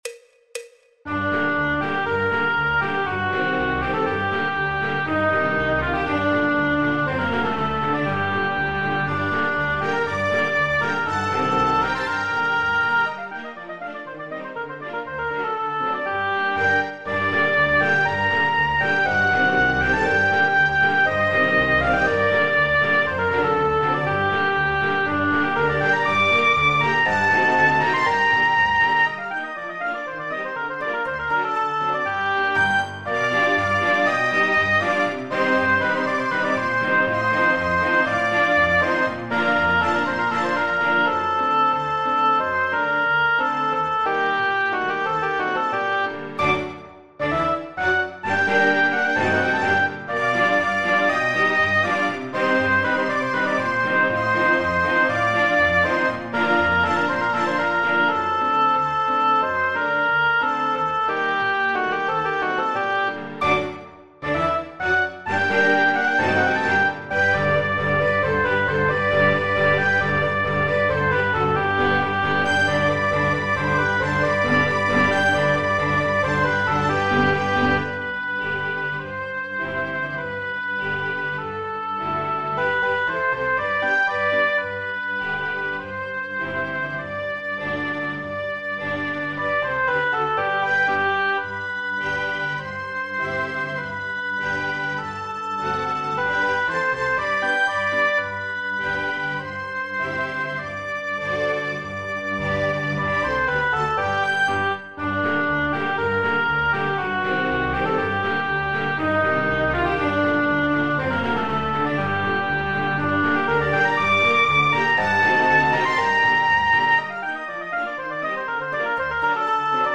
El MIDI tiene la base instrumental de acompañamiento.
Popular/Tradicional